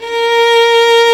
Index of /90_sSampleCDs/Roland L-CD702/VOL-1/STR_Violin 1-3vb/STR_Vln3 _ marc
STR VLN3 A#3.wav